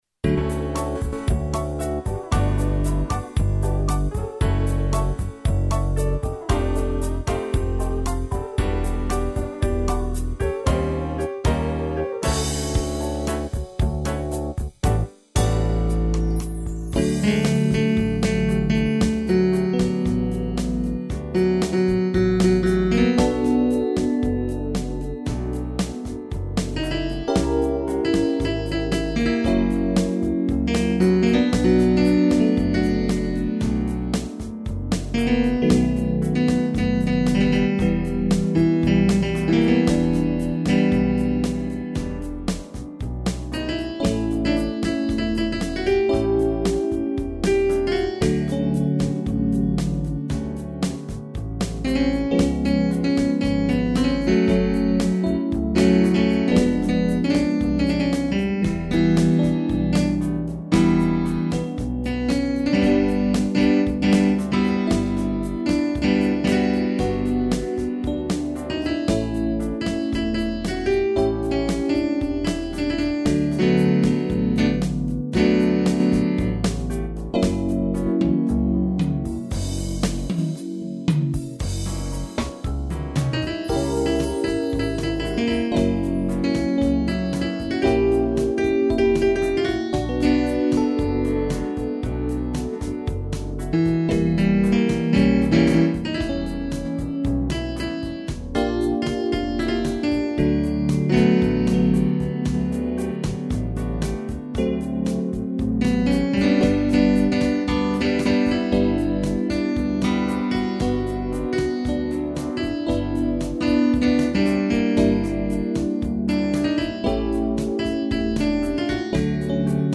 (instrumental) Soneto 003